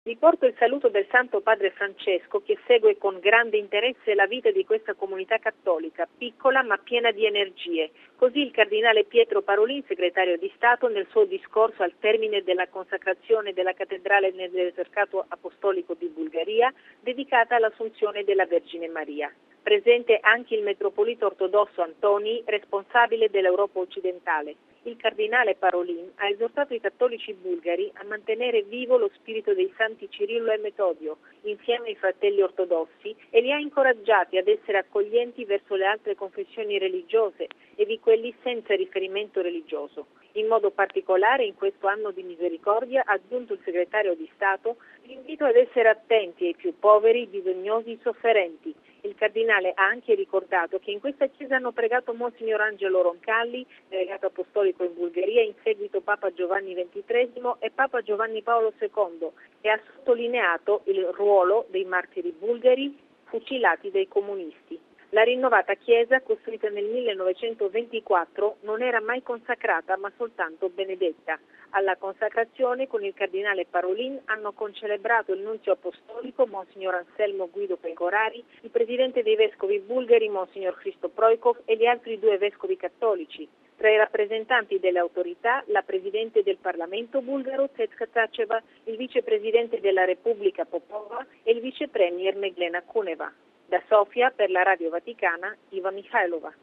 Questa mattina a Sofia, il cardinale ha presieduto la liturgia di consacrazione della cattedrale “Assunzione della Vergine Maria”, sede dell’Esarcato apostolico in Bulgaria. Da Sofia, l'inviata